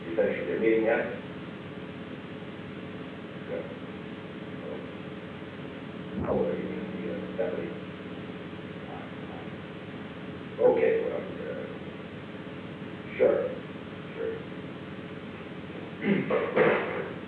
Secret White House Tapes
Conversation No. 442-40
Location: Executive Office Building
The President talked with an unknown person.